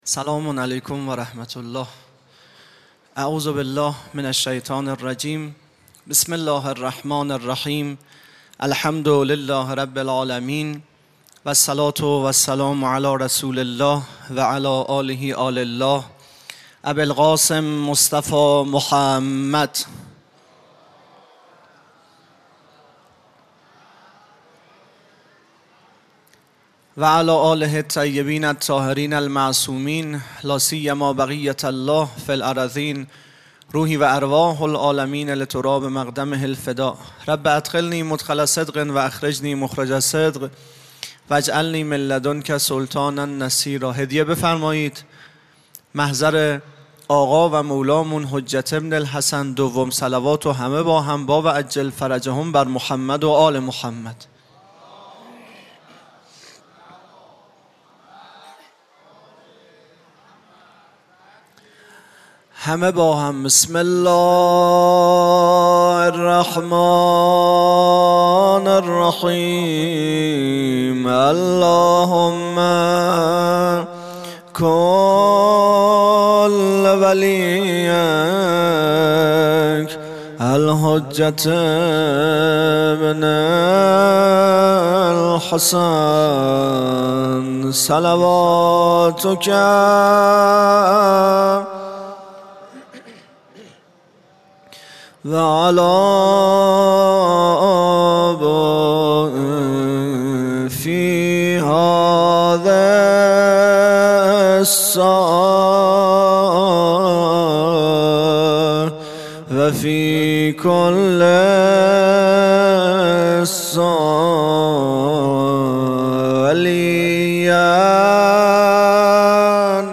خیمه گاه - هیئت بچه های فاطمه (س) - سخنرانی | ۳۰ تیر ۱۴۰۲